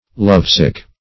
Love-sick \Love"-sick`\, a.